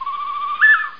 bird_1.mp3